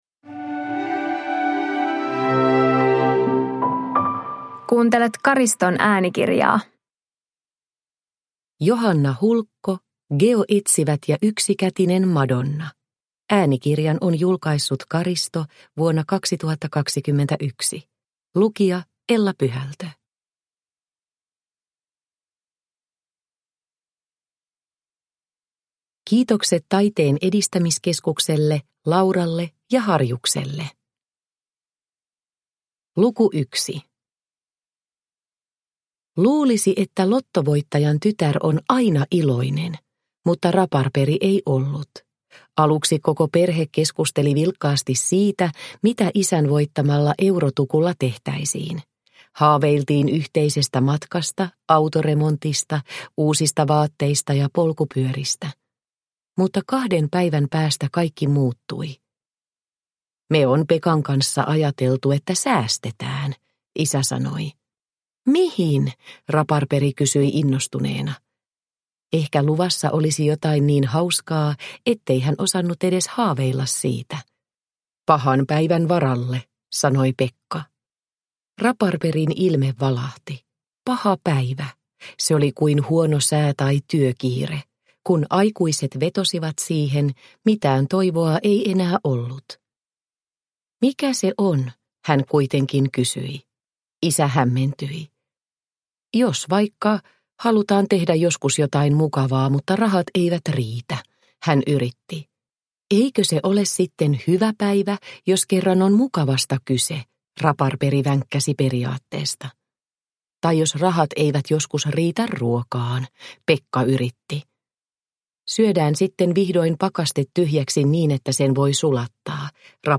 Geoetsivät ja yksikätinen madonna – Ljudbok – Laddas ner